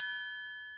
Triangle(1)_22k.wav